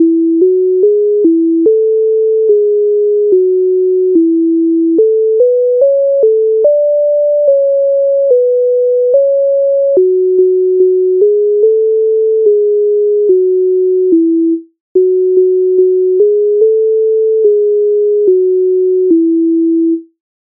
MIDI файл завантажено в тональності E-dur
Ой піду я лугом Українська народна пісня з обробок Леонтовича с.109
Ukrainska_narodna_pisnia_Oj_pidu_ia_luhom.mp3